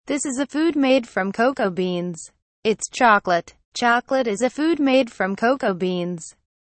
Conversation Dialog #1: